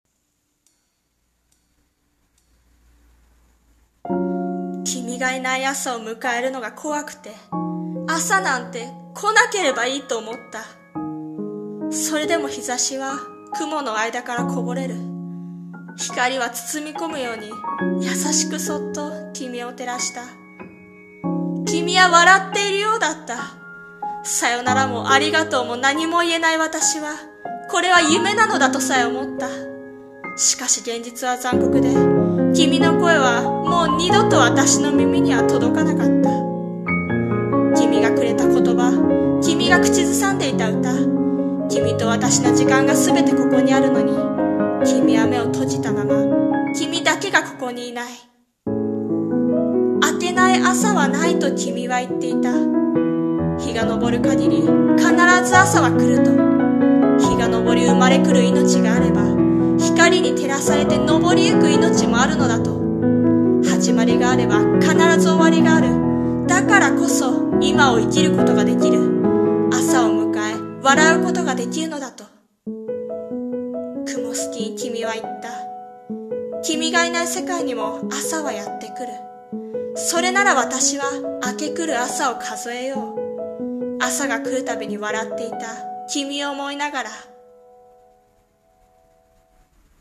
さんの投稿した曲一覧 を表示 【朗読台本】雲透きに君想う